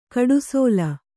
♪ kaḍusōla